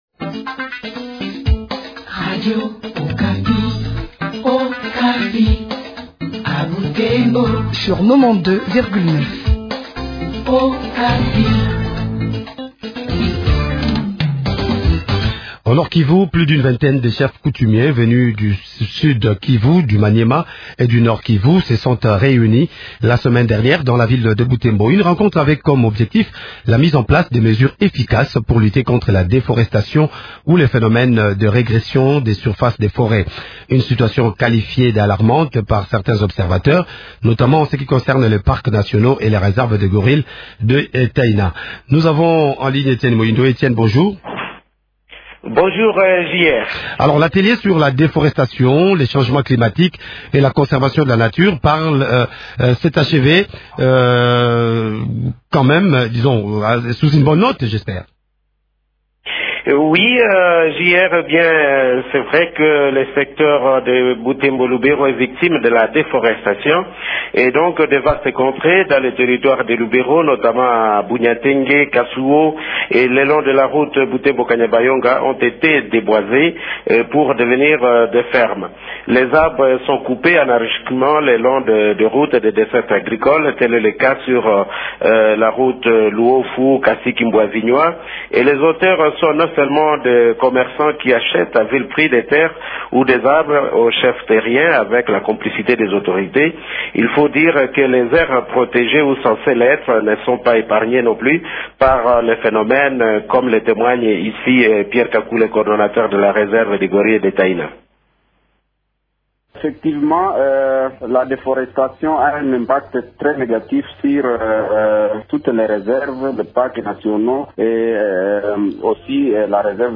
en parle avec José Endundu Bononge, ministre de l’environnement, conservation de la nature et tourisme.